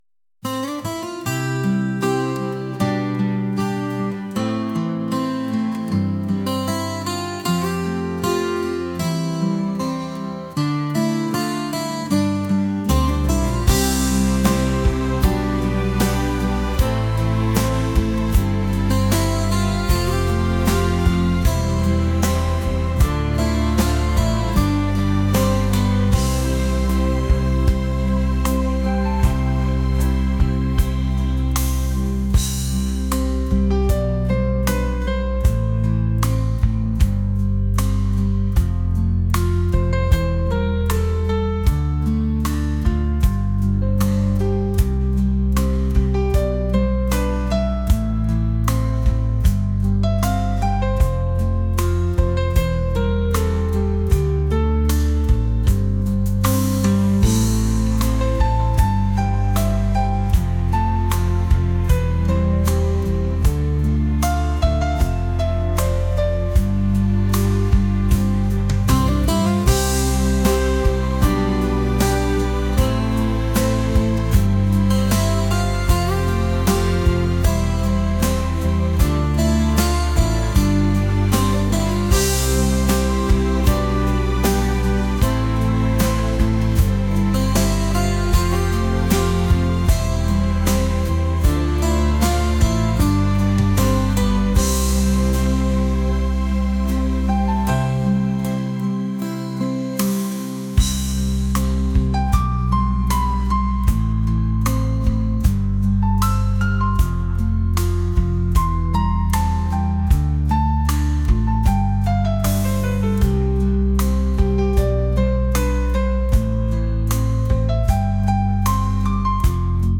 acoustic | pop | lofi & chill beats